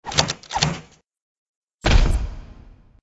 SA_rubber_stamp.ogg